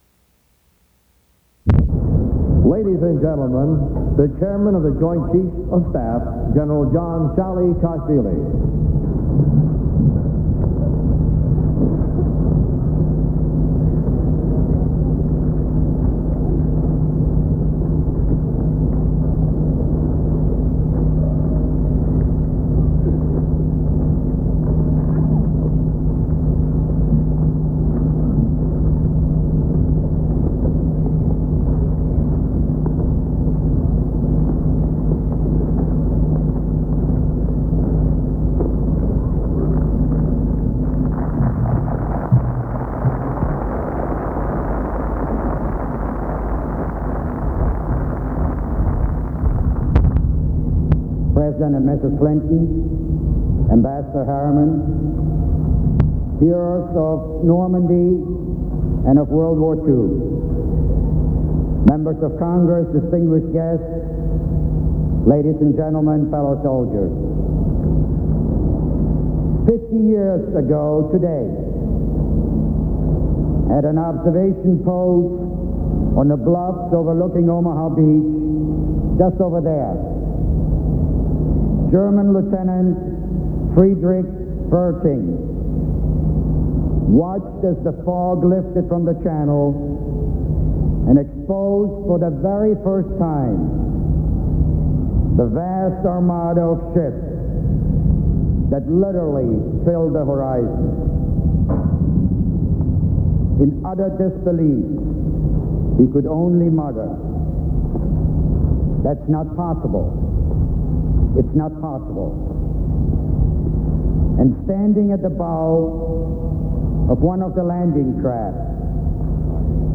General John Shalikashvili, head of the Joint Chiefs of Staff, speaks at Normandy on the fiftieth anniversary of D-Day